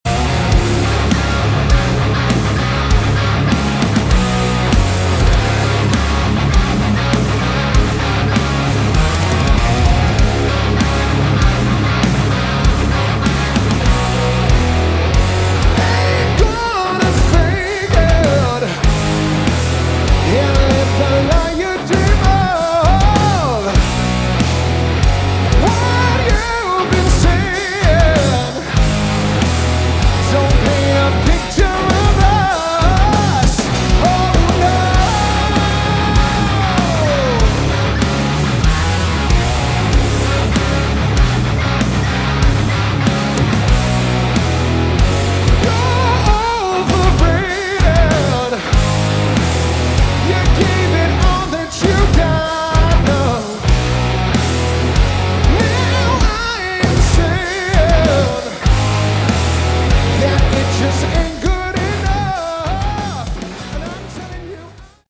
Category: Hard Rock
lead vocals
guitar
bass guitar
drums, percussion